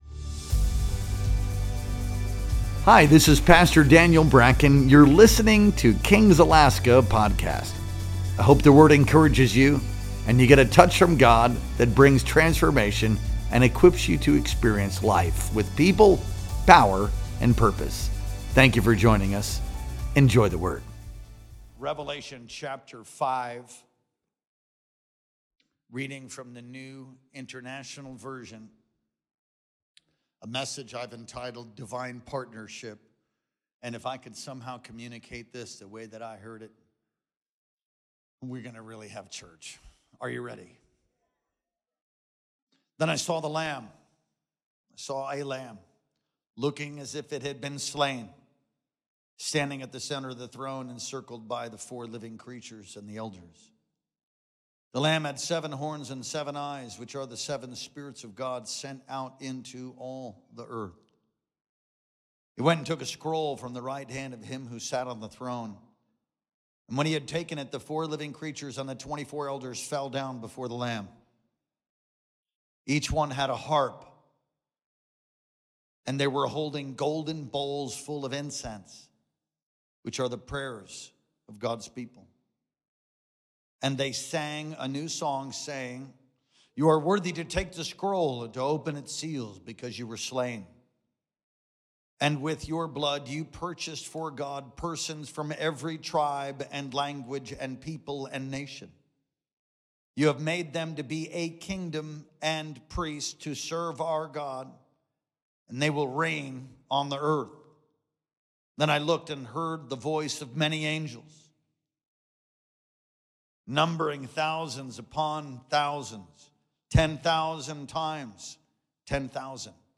Our Wednesday Night Worship Experience streamed live on April 23rd, 2025.